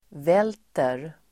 Uttal: [v'el:ter]